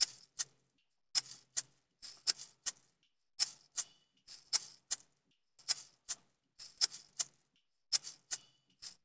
EPH SHAKER.wav